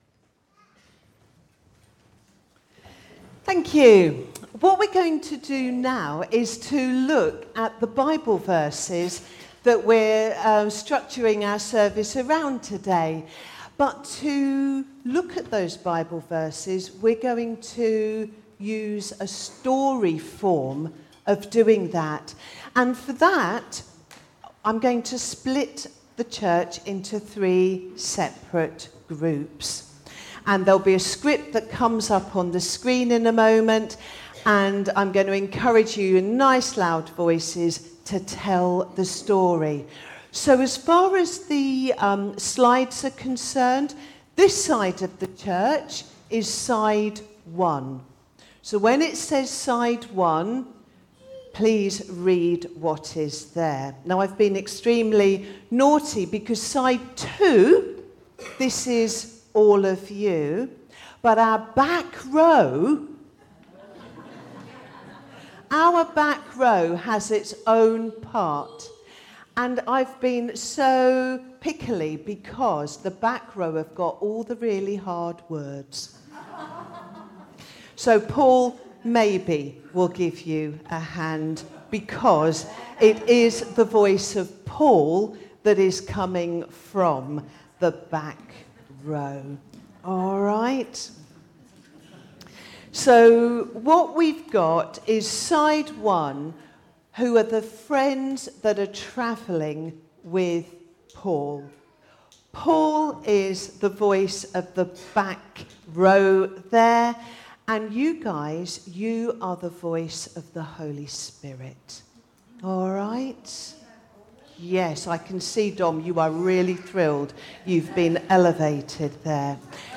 A message from the series "Your Born Identity."